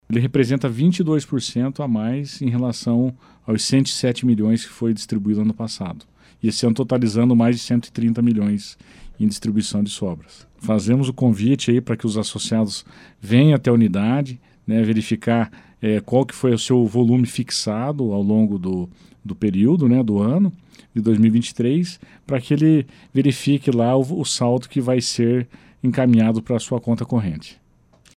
ENTREVISTA-COCAMAR-03-1.mp3